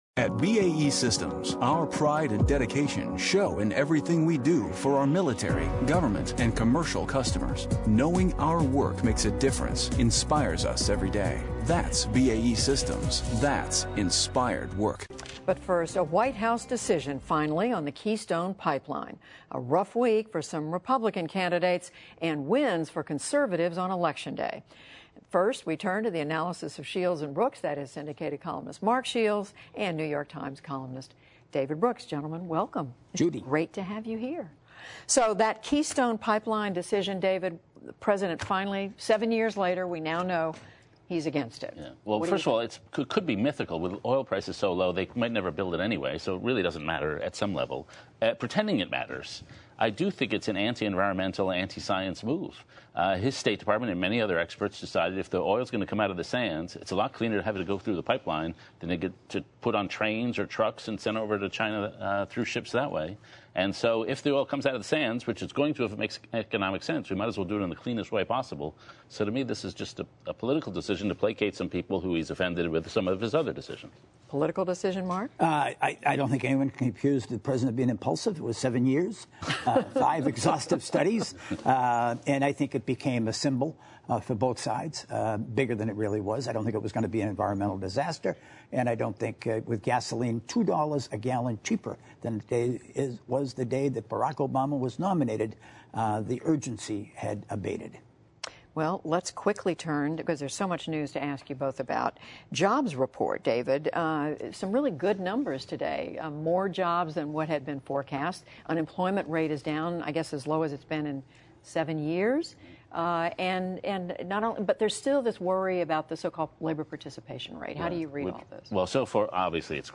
President Obama rejected the Keystone XL pipeline after seven years, and the October jobs report offered a brighter labor outlook. Judy Woodruff discusses the week’s news with syndicated columnist Mark Shields and New York Times columnist David Brooks, including claims about Ben Carson’s past, questions about Marco Rubio’s finances and revelations in a new biography about George H.W. Bush.